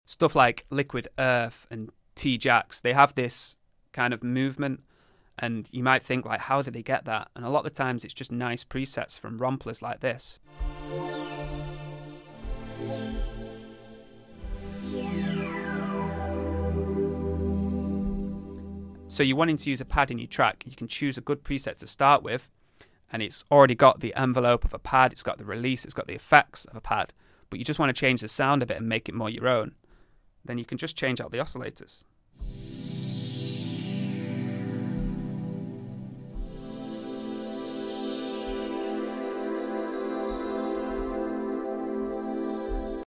We take a deep dive into the powerful KORG Triton. This synth brings back the lush pads, rich stabs, and versatile sounds that defined the era.